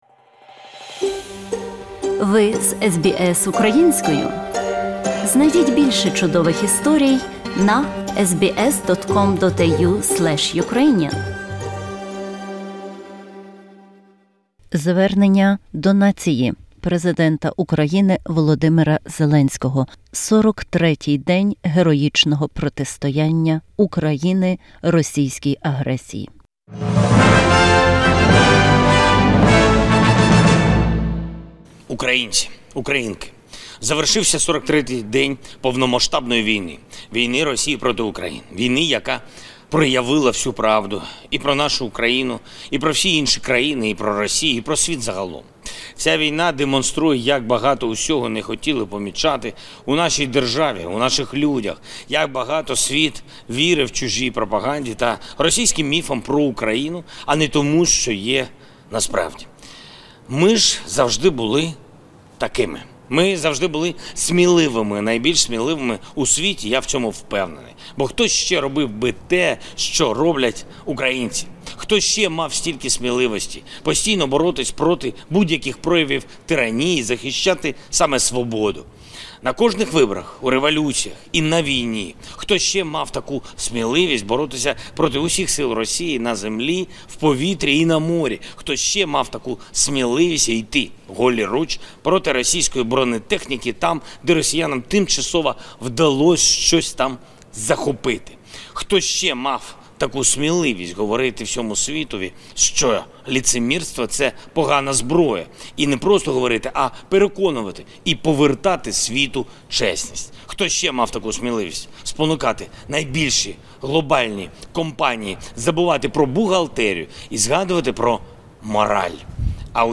Звернення Президента України В. Зеленського